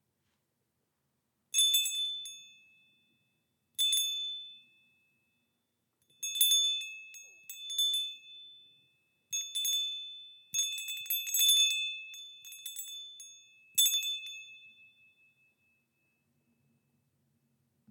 Japanese Pyramid Wind Chime made from cast iron
With each breeze, this chime offers a deep, resonant tone that invites tranquility and focus, grounding your environment with every gentle sound.
Cast iron, made in Japan
Pyramid-Windchime.mp3